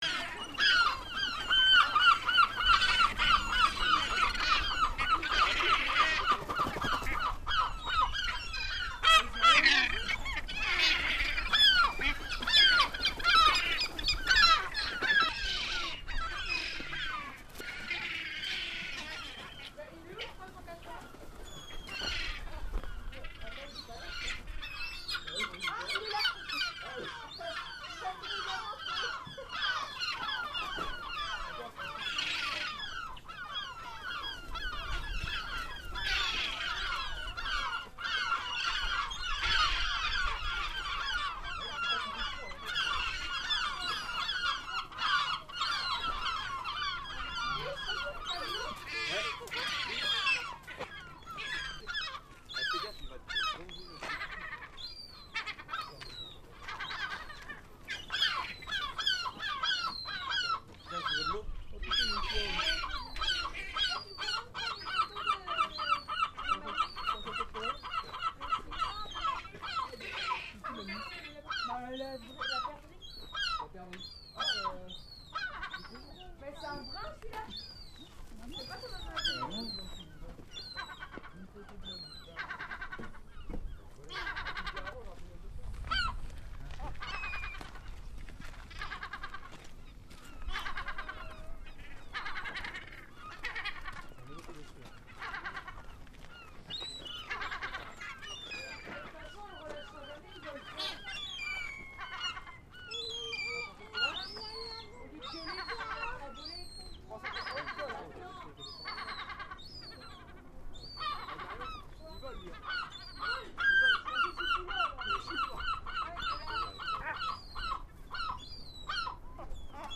oiseaux de mer